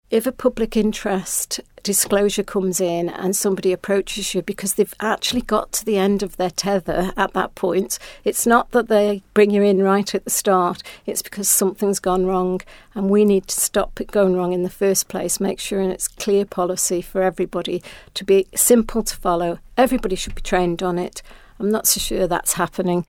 Speaking recently to Manx Radio, Ms Edge says it's an important issue to get right: Listen to this audio